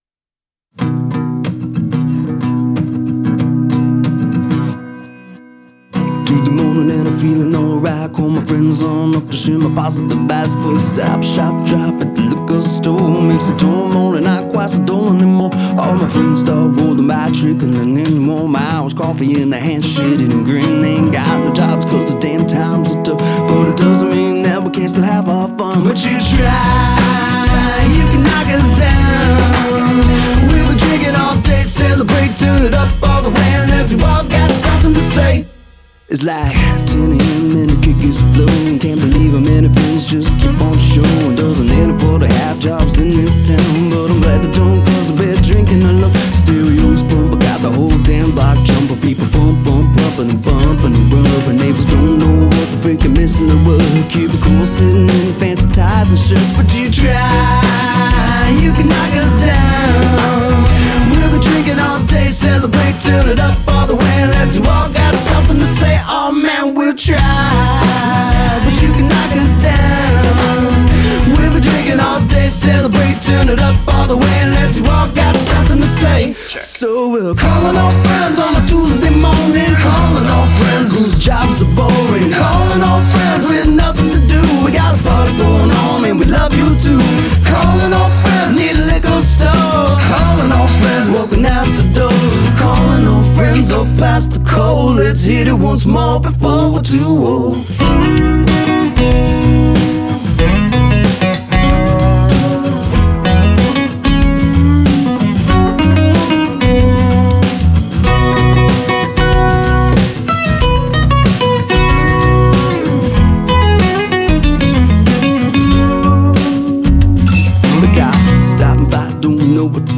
I’ve tried to master it myself, but I’m not sure how it compares and I’m a little worried I might be squashing it too much.